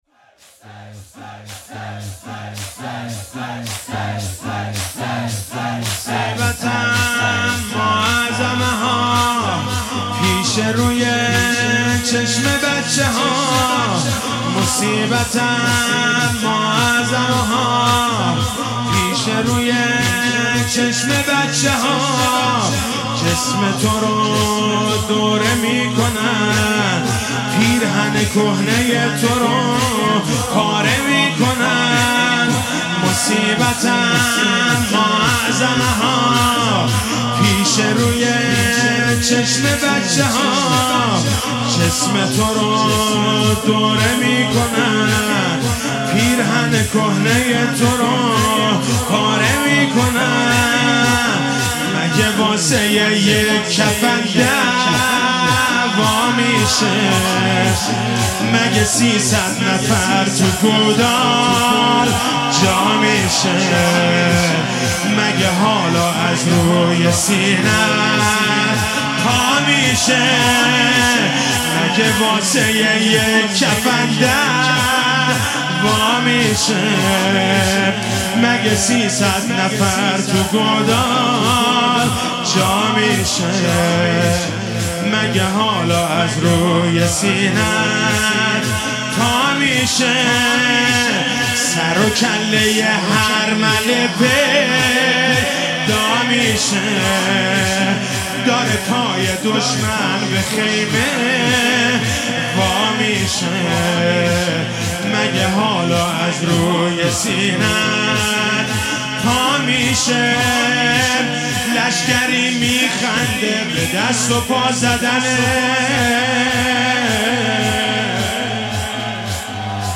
شهادت امام صادق علیه السلام1400